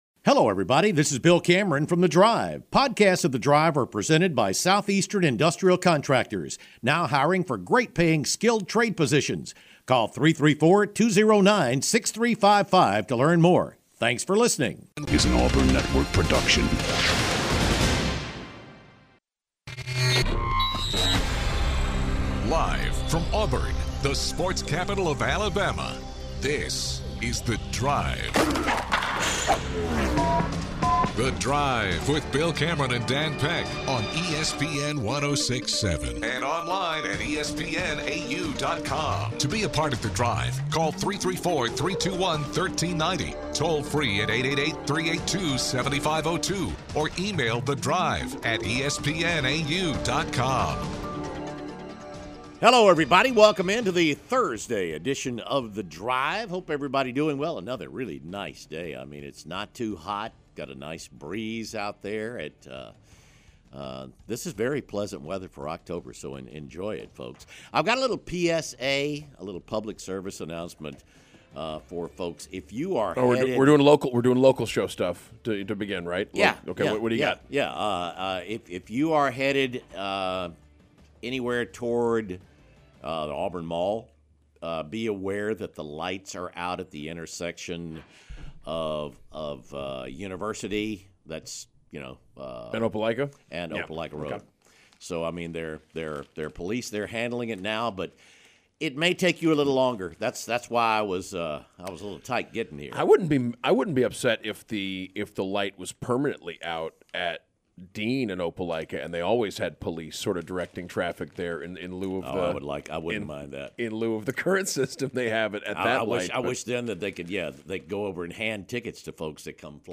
Callers wonder about the future of Auburn's football program.